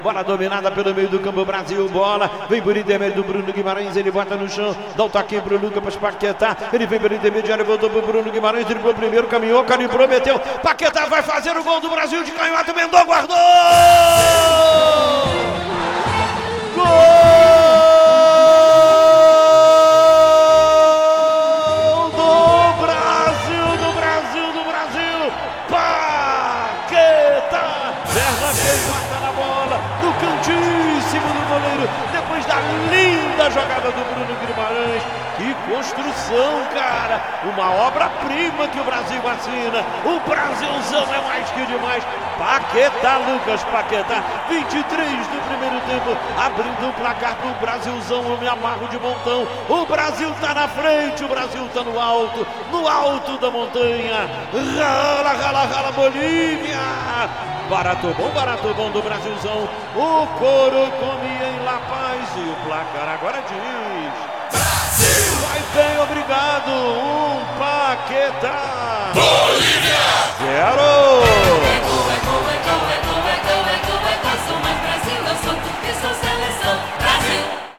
Ouça os gols da vitória do Brasil sobre a Bolívia com a narração de Luiz Penido